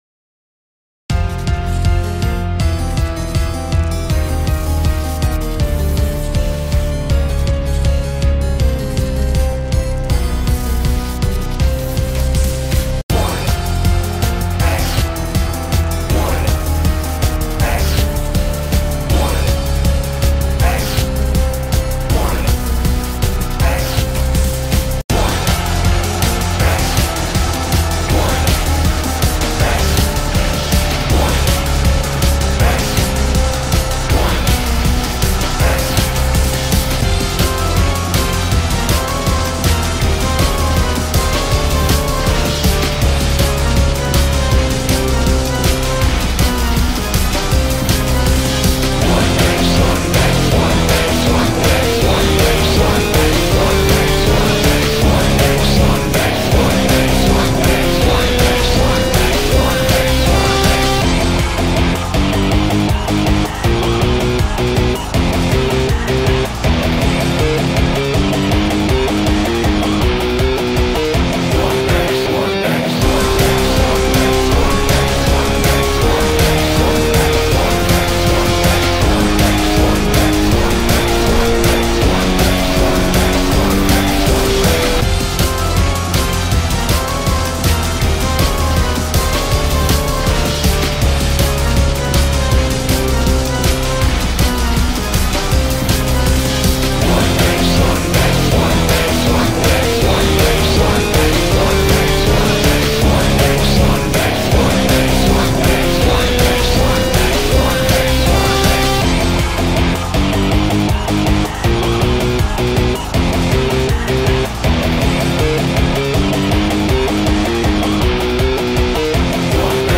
Вариант 5 ремейк (все дистанции)